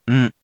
We’re going to show you the character, then you you can click the play button to hear QUIZBO™ sound it out for you.
In romaji, 「ん」 is transliterated as 「n」which sounds sort of like how you would pronounce the regular「n」or a nasalised「ng」depending on the placement in the word.